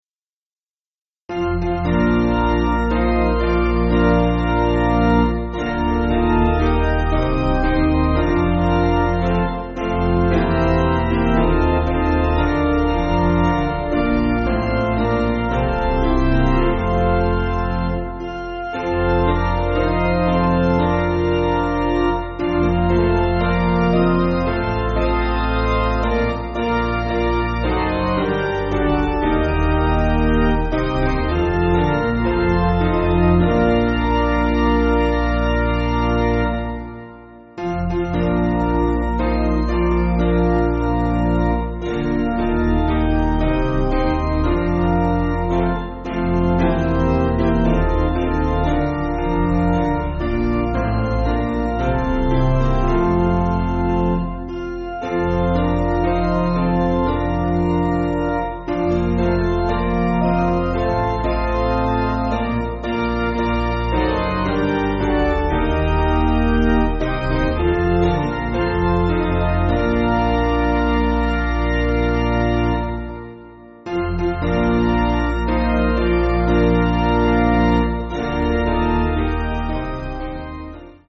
Basic Piano & Organ
(CM)   5/Bb